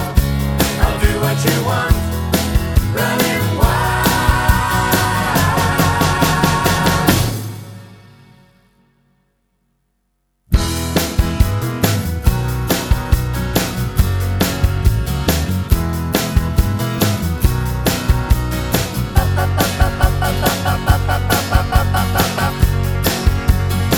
Pop (1970s)